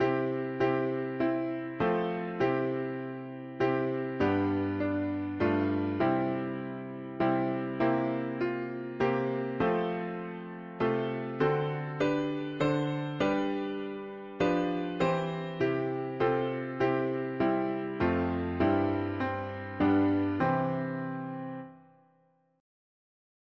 Key: C major